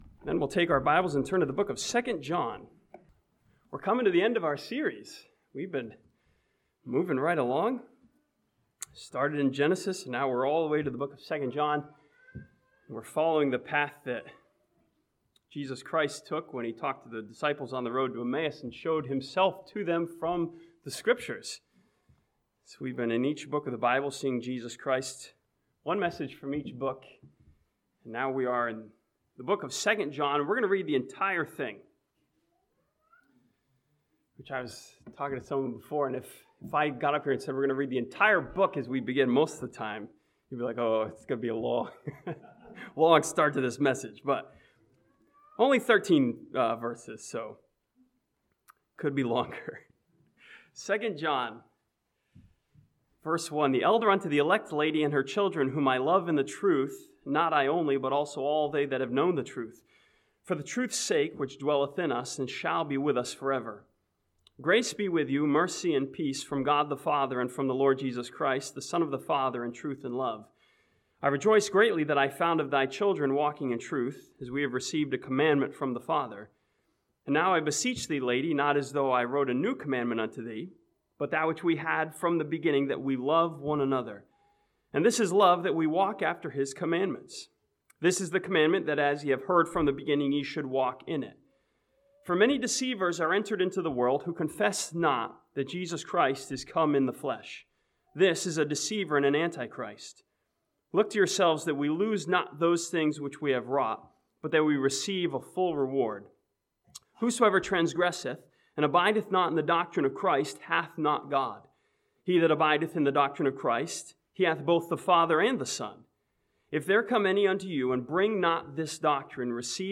This sermon from 2 John sees Jesus in the flesh who had a physical body and literally died and rose again for us